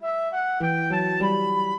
flute-harp
minuet9-12.wav